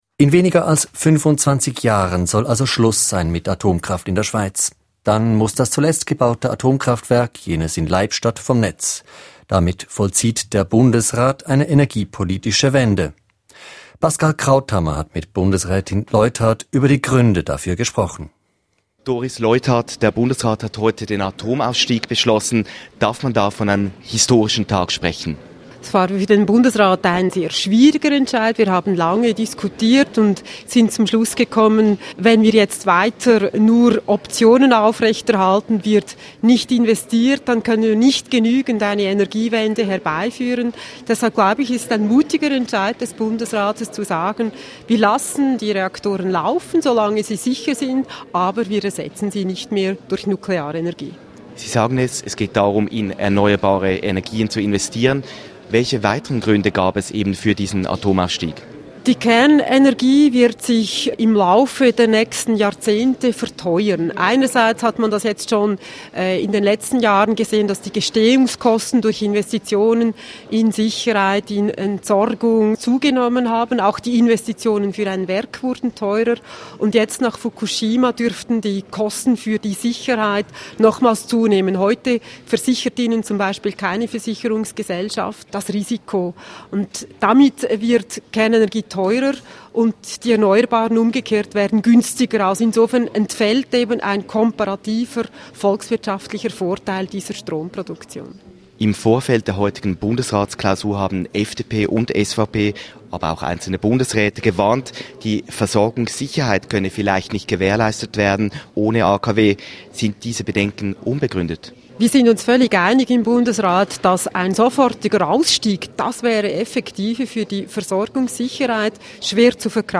Interview mit Bundesrätin Doris Leuthard.